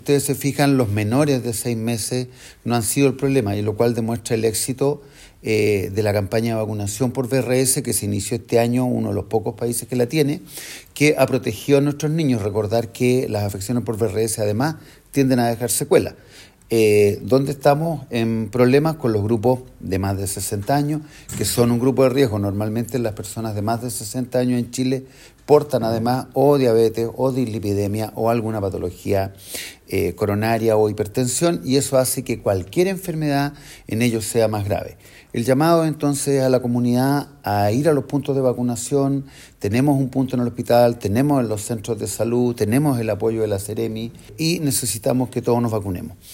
Por este motivo Rodrigo Alarcón, reiteró a la comunidad el llamado para vacunarse contra la influenza y covid-19.